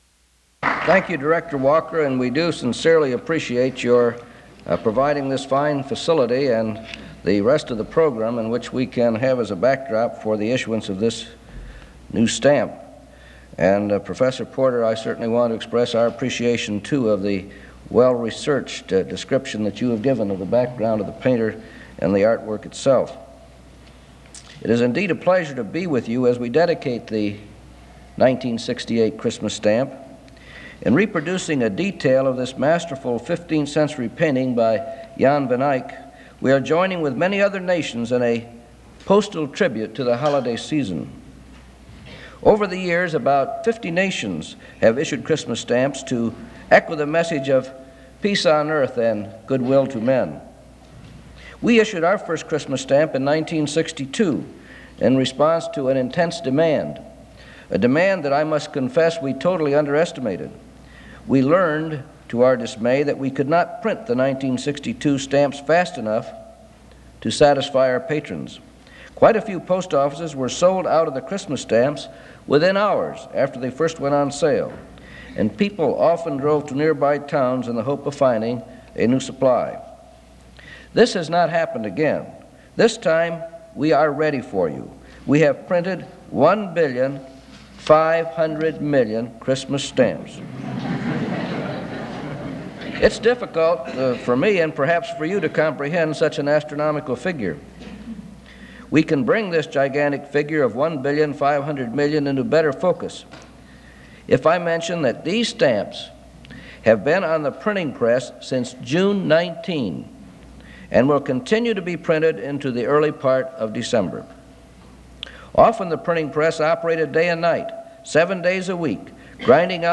Broadcast by U.S. Post Office, Nov. 1, 1968.